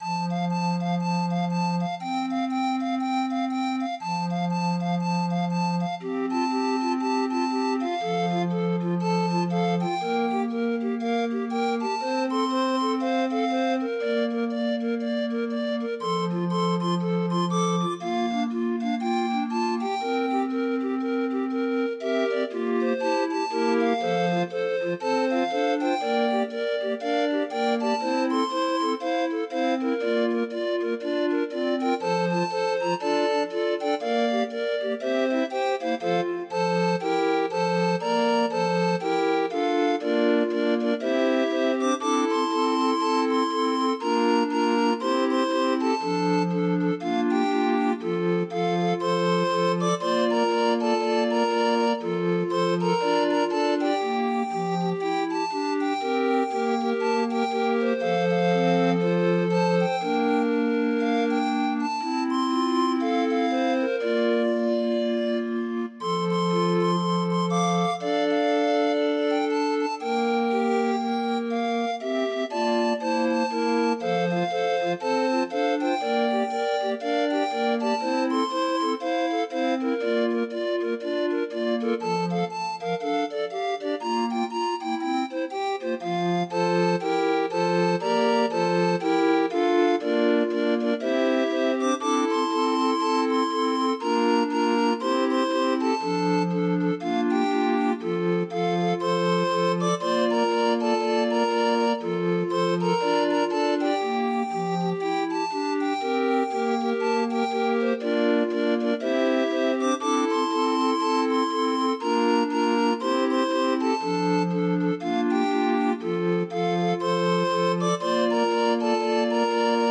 Demo of 20 note MIDI file